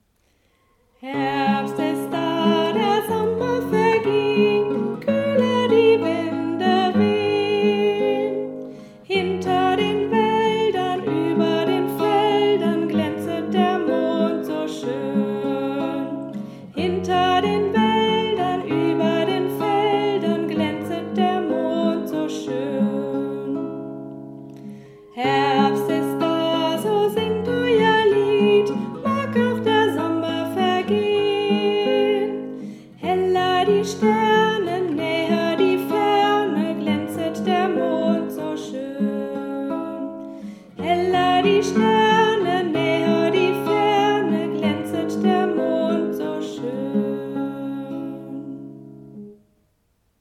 Dieses Lied wurde für Sie eingesungen, um einen ersten Eindruck zu bekommen, aber auch zum Mitsingen und Einüben.